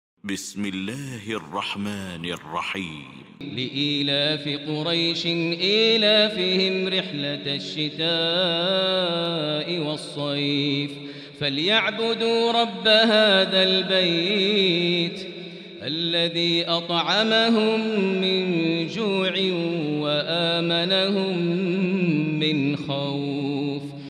المكان: المسجد الحرام الشيخ: فضيلة الشيخ ماهر المعيقلي فضيلة الشيخ ماهر المعيقلي قريش